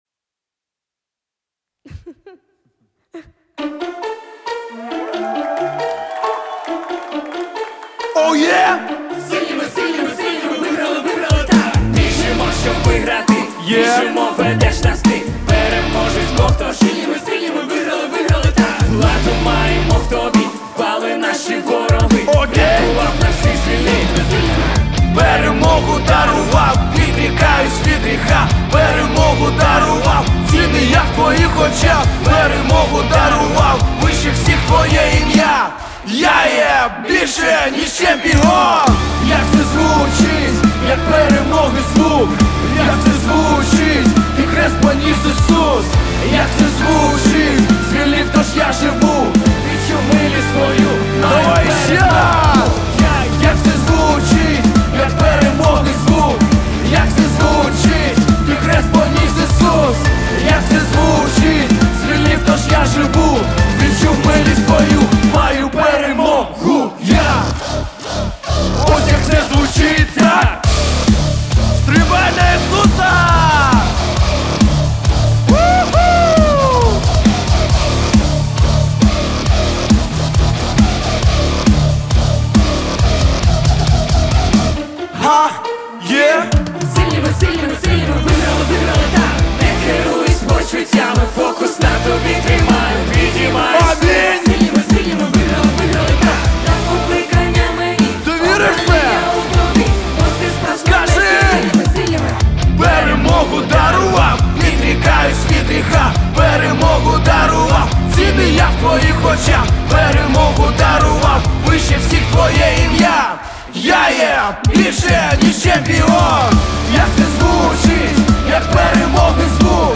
717 просмотров 873 прослушивания 48 скачиваний BPM: 136